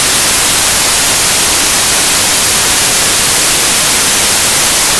whitenoise.wav